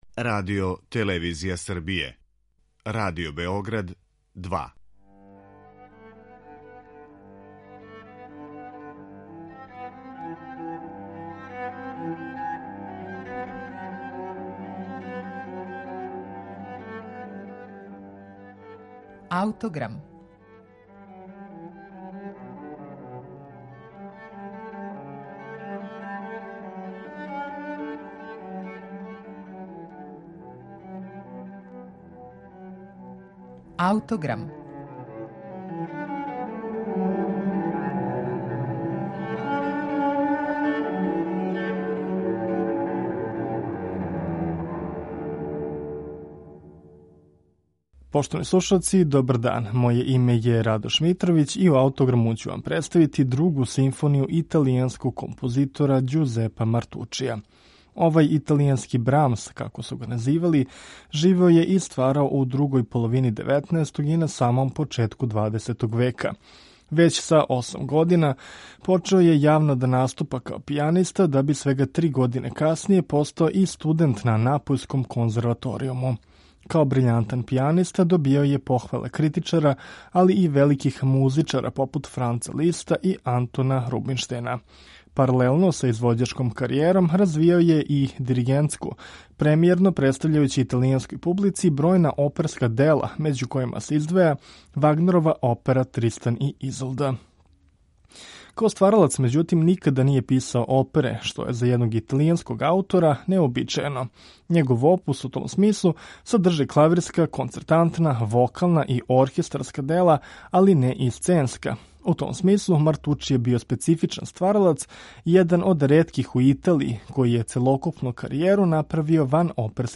Неговао је позно романтичарски израз и писао дела различитих жанрова. Луцидност овог аутора ћемо чути и у његовој Другој симфонији, коју изводи Оркестар Филхармонија, под управом Франческа Давалоса.